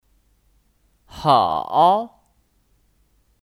好 (Hǎo 好)